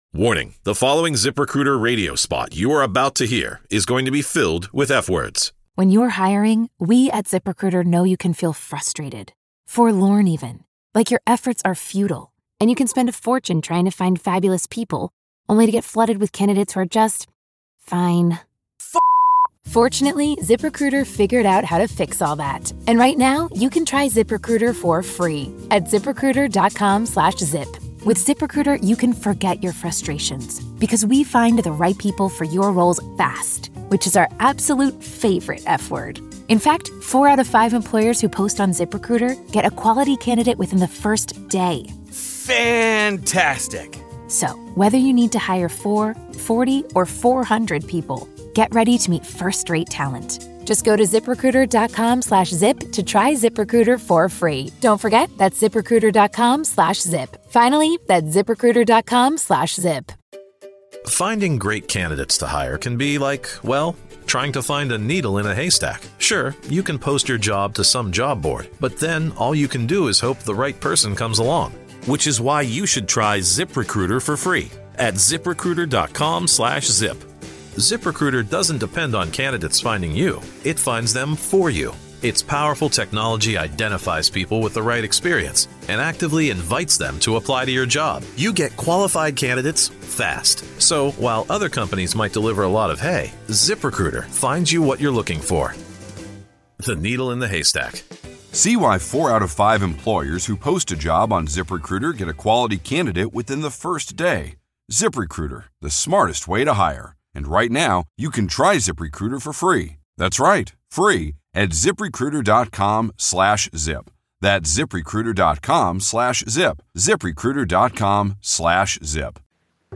Op de achtergrond hoort u heel even podcasthond Billie, die uiteraard niet ontbreken kon.
Veel dank gaat daarom uit aan ´het internet´ die deze opname mogelijk maakte.